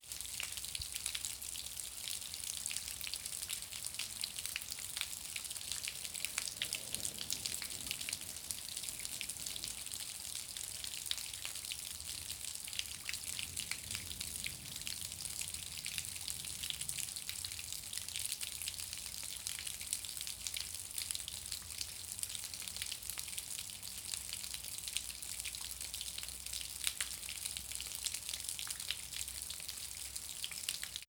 Water Dripping.wav